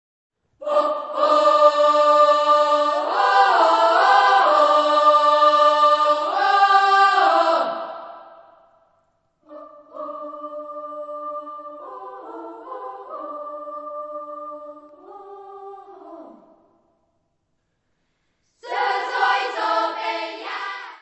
: stereo; 12 cm
Music Category/Genre:  World and Traditional Music